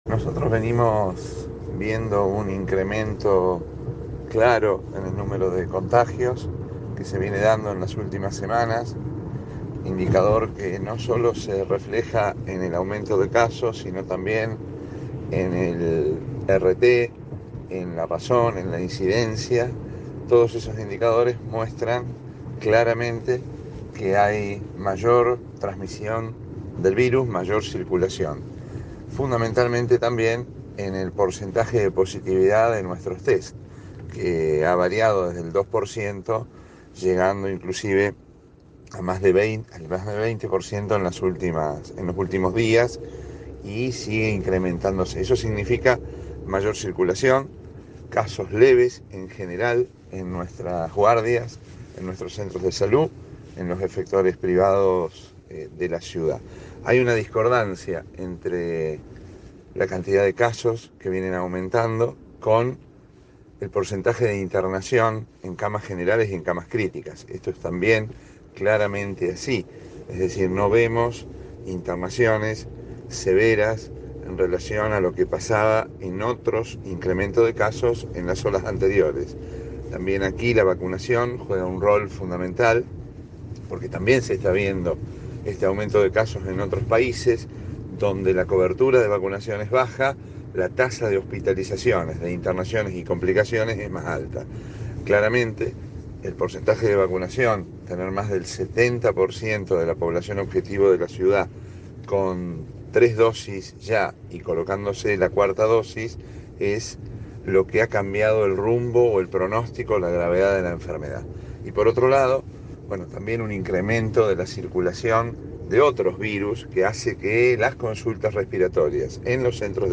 En Cadena 3 Rosario, el funcionario destacó que el aumento se visualiza “fundamentalmente” en el porcentaje de positividad en los test.